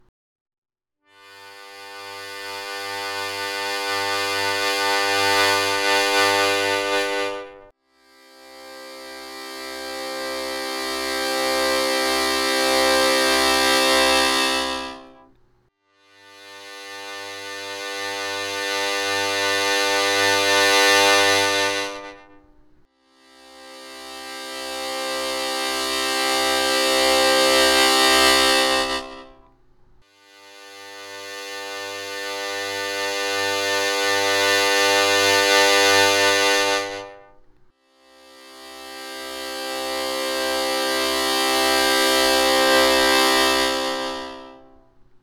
Играть от тихого к среднему (выводя звук с минимальной громкости до средней). Чередовать аккорды на вдох и на выдох на первых трёх отверстиях: -(123)+(123)